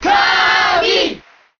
Kirby_Cheer_Japanese_SSBM.ogg